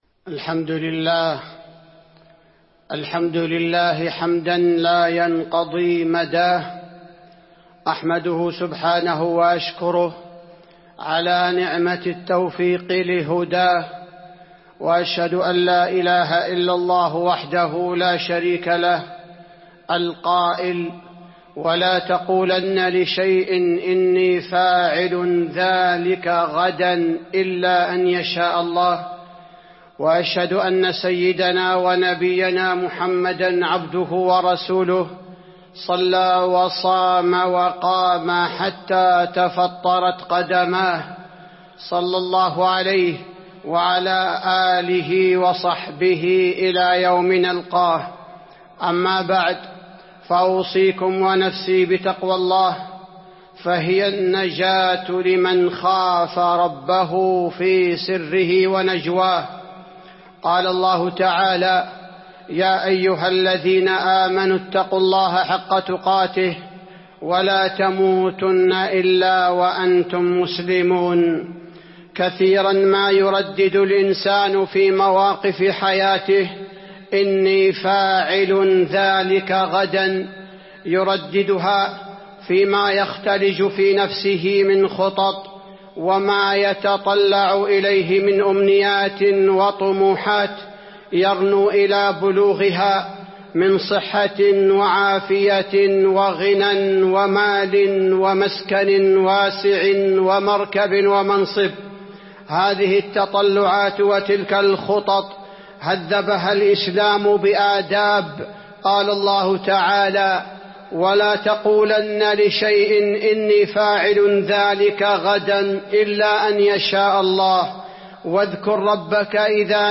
تاريخ النشر ١٤ رجب ١٤٤٢ هـ المكان: المسجد النبوي الشيخ: فضيلة الشيخ عبدالباري الثبيتي فضيلة الشيخ عبدالباري الثبيتي إني فاعل ذلك غداً